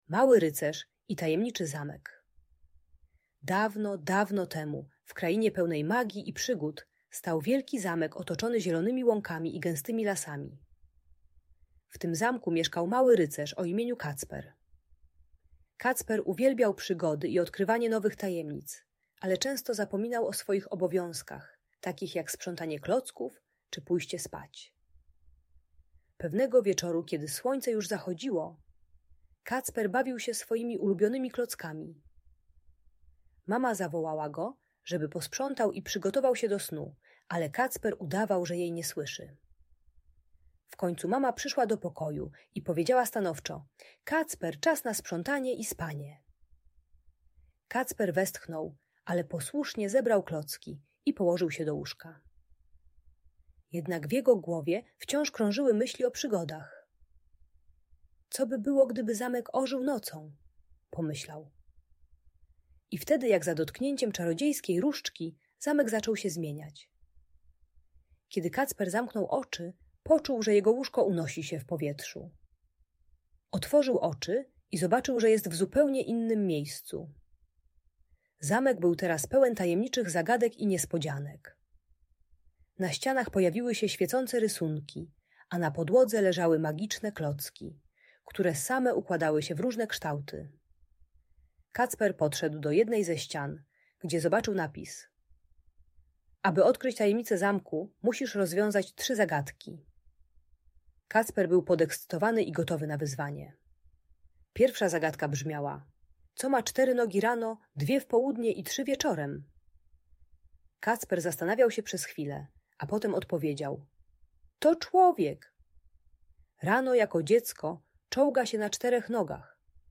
Historia Małego Rycerza i Tajemniczego Zamku - Audiobajka dla dzieci